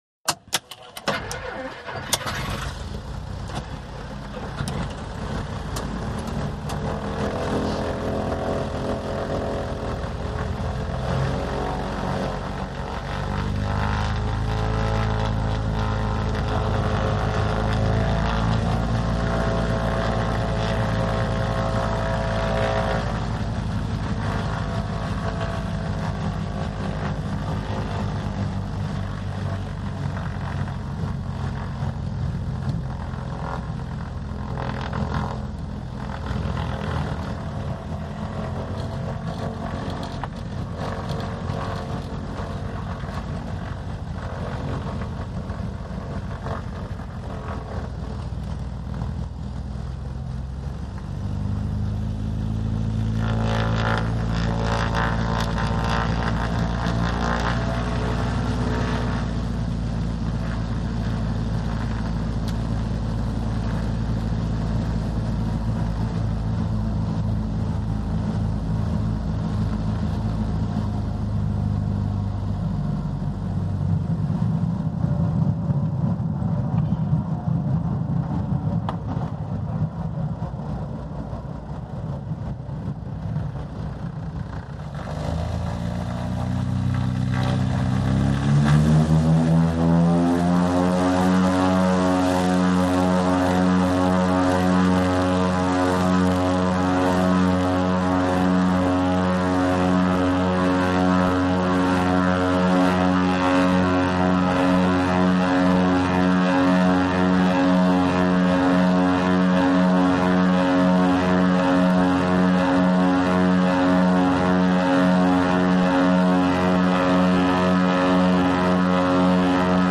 AIRCRAFT PROP TWIN TURBO: INT: Start, taxi, take off, steady flight, landing, taxi, switch off.